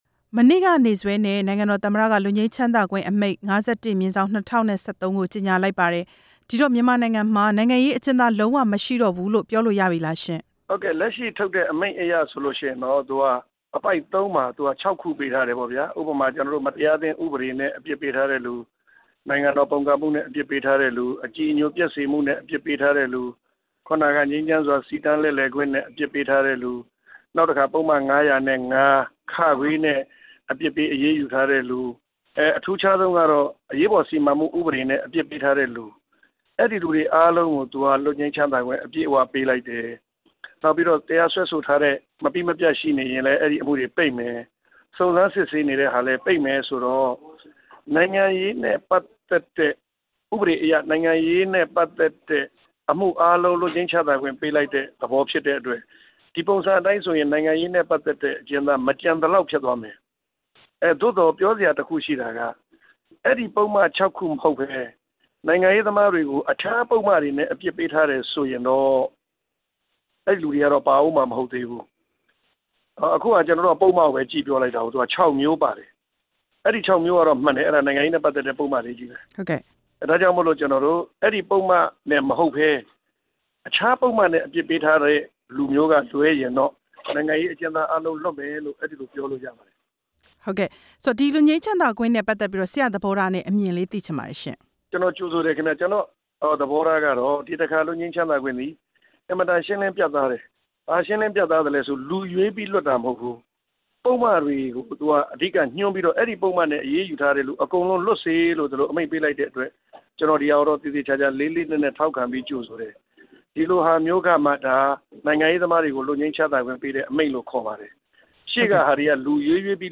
ဦးကိုနီနဲ့ ဆက်သွယ် မေးမြန်းချက်